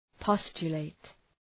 Προφορά
{‘pɒstʃə,leıt}